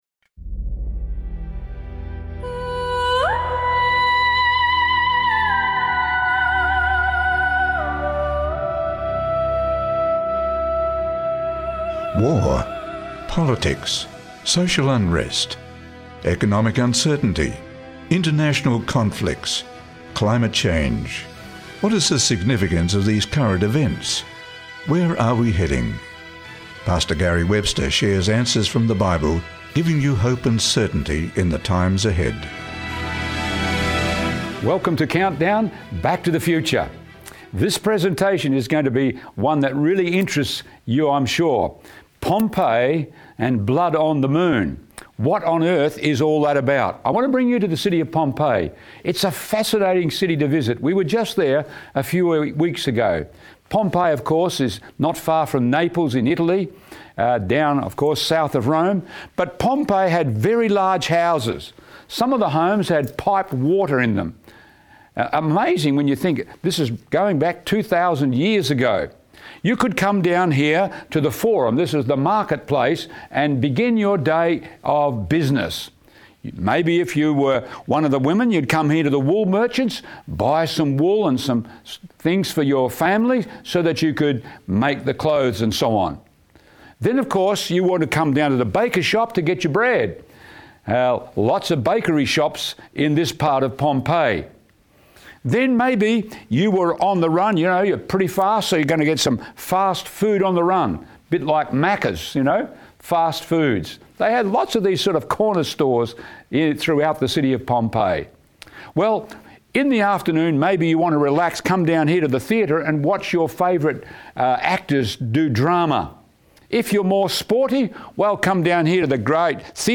This captivating presentation explores the historical events and cosmic signs that signal the end times, urging us to heed the warnings and prepare for the greatest event of the ages - the restoration of our lost inheritance.